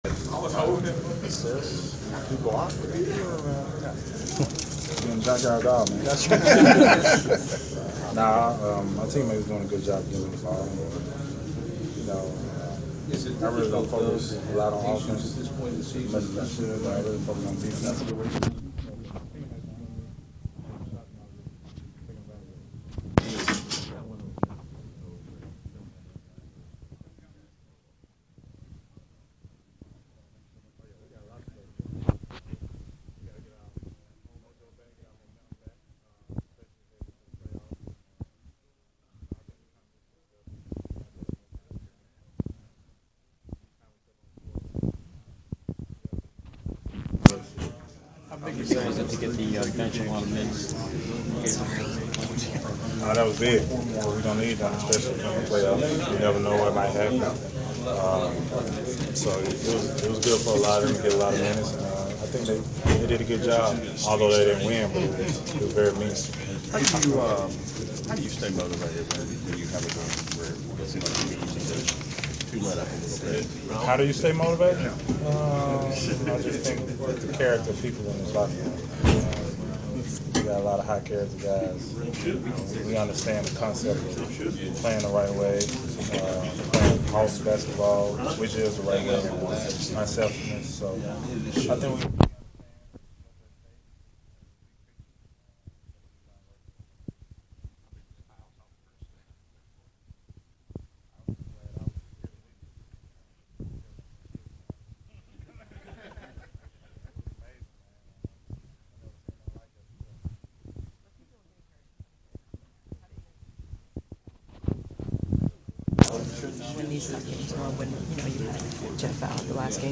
Inside the Inquirer: Postgame presser with Atlanta Hawks’ player DeMarre Carroll (3/30/15)
Demarre Carroll interview after Milwaukee Bucks’ contest (3/30/15)